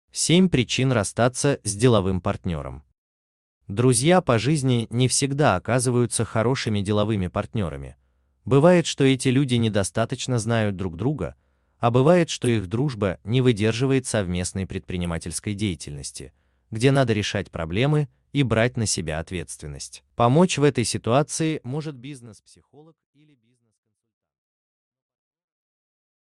Аудиокнига 7 причин расстаться с деловым партнером | Библиотека аудиокниг